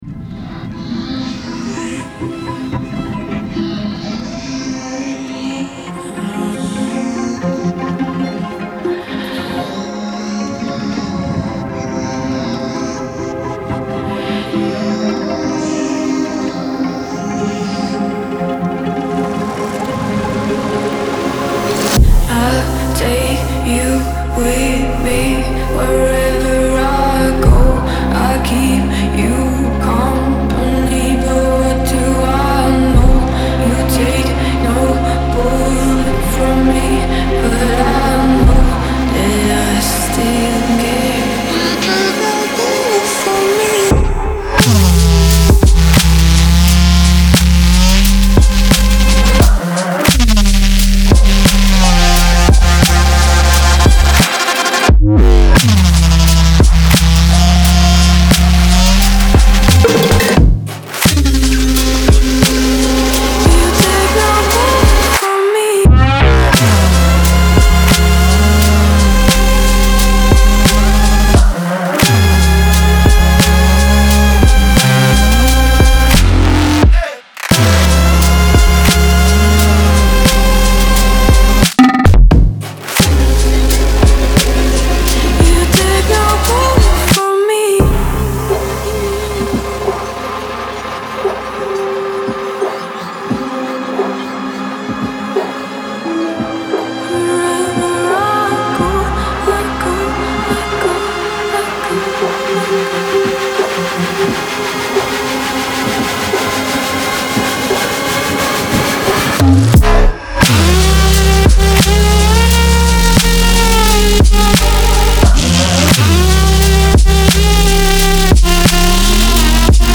Жанр: dnb
Drum and Bass